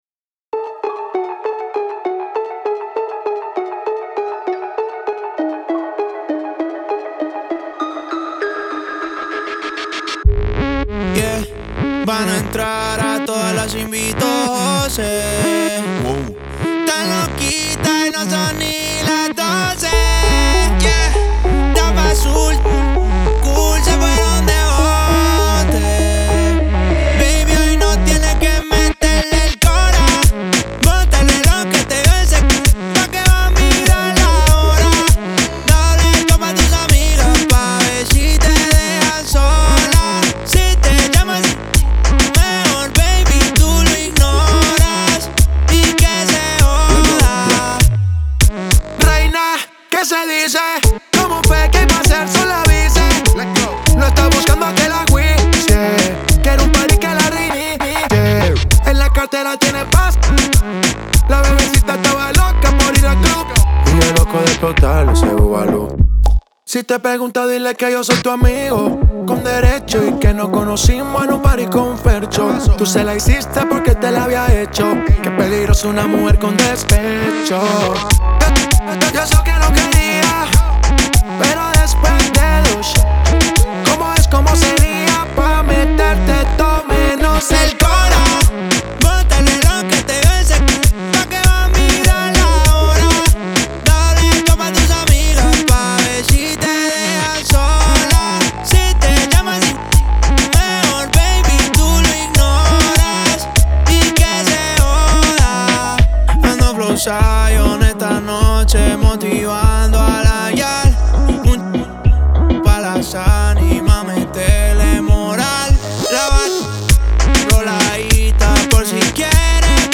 Genre: Ton Ton.